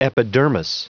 Prononciation du mot epidermis en anglais (fichier audio)
Prononciation du mot : epidermis